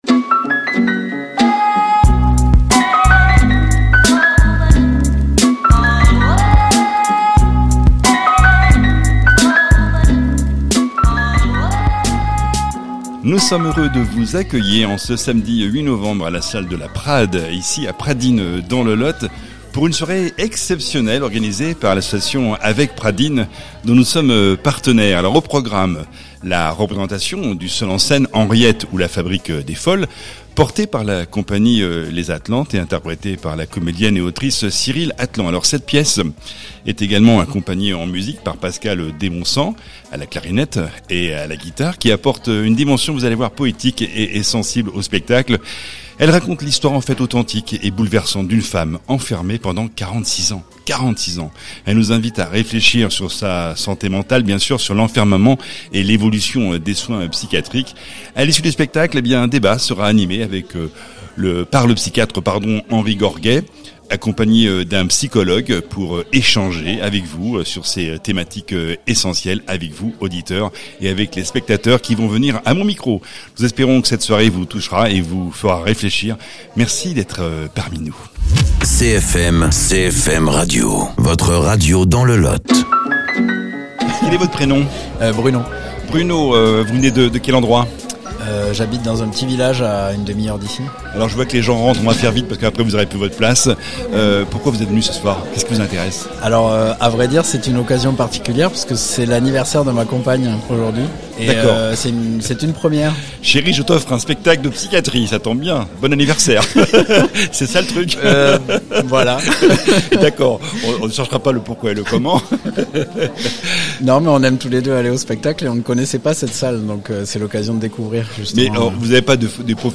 Emission immersive à la salle La prade à Pradines le 8 novembre 2025 pour
Émissions